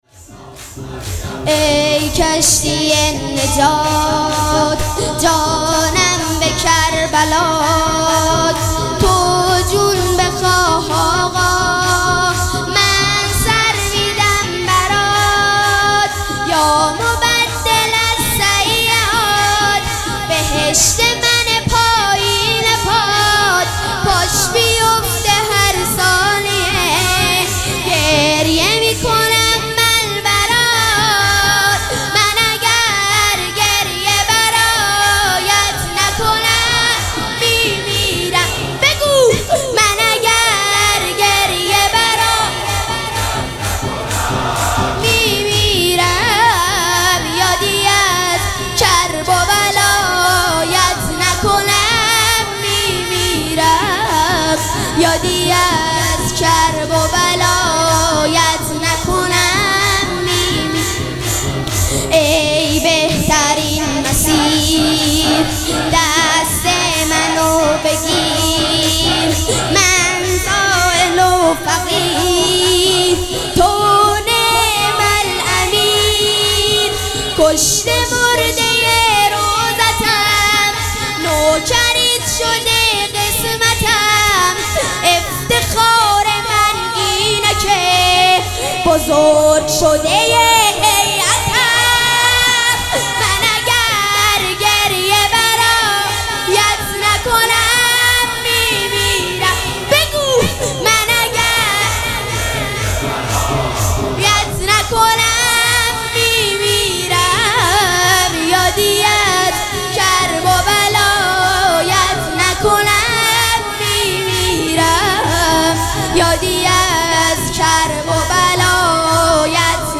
مراسم عزاداری شب شهادت امام حسن مجتبی(ع)
شور